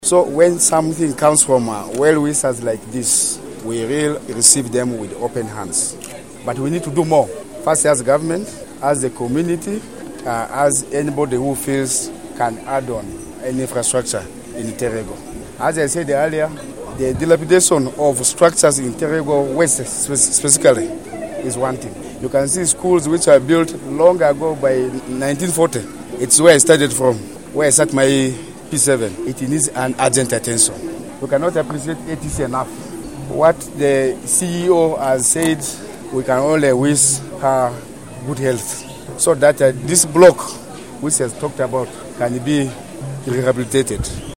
The area Member of Parliament for Terego West, Joel Leku